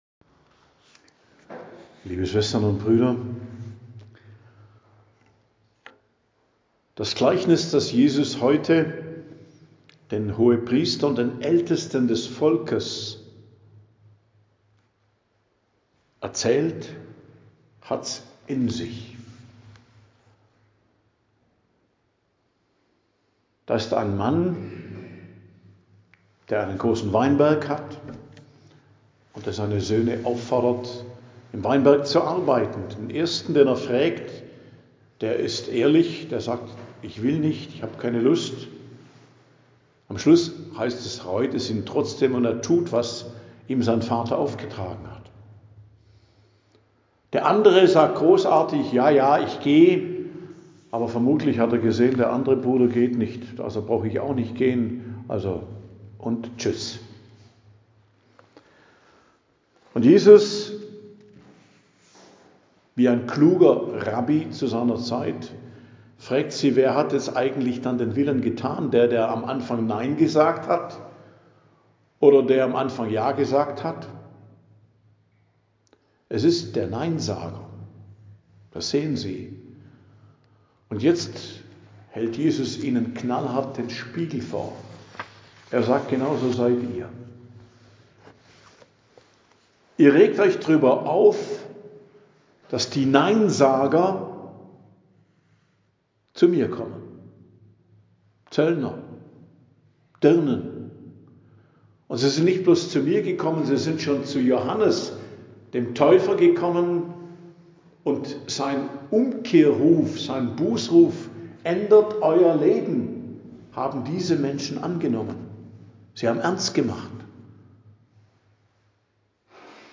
Predigt am Dienstag der 3. Woche im Advent, 16.12.2025